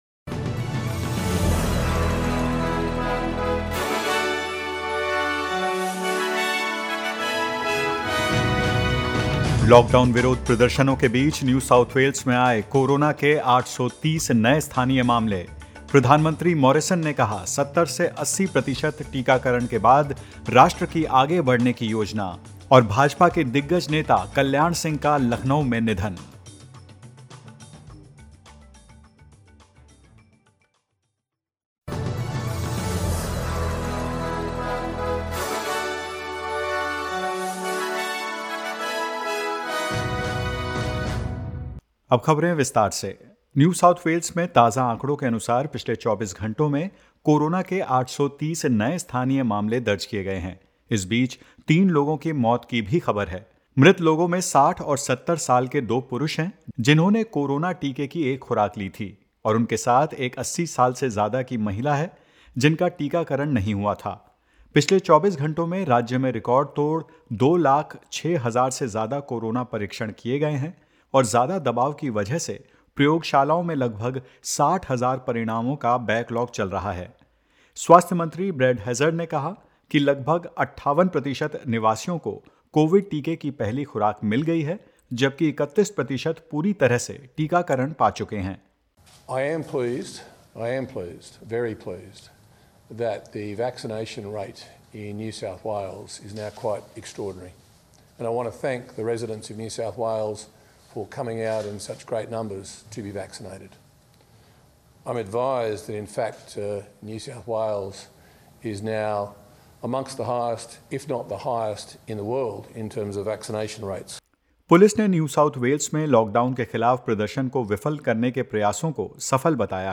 In this latest SBS Hindi News bulletin of Australia and India:65 new COVID-19 cases recorded in Victoria, with at least 21 linked to the Shepparton outbreak; Queensland records another day of zero new COVID-19 cases in the last 24 hours and more.